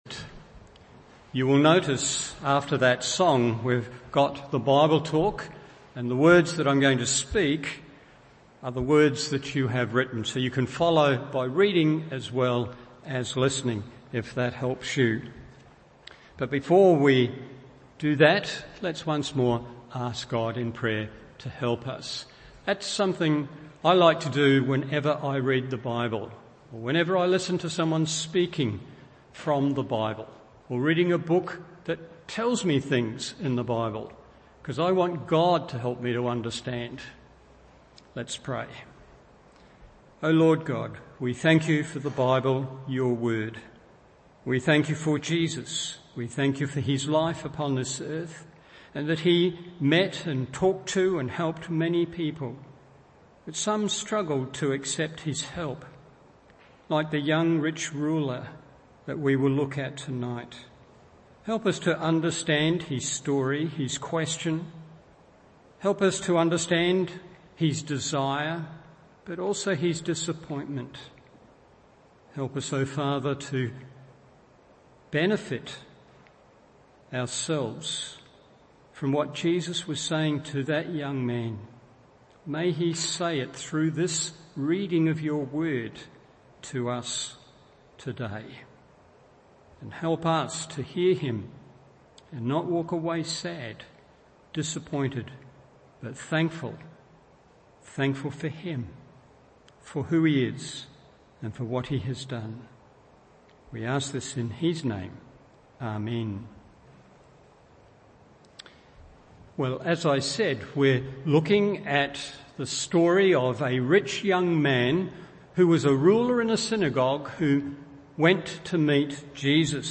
Evening Service Meeting Jesus: The Rich Young Ruler – Do I Need Jesus?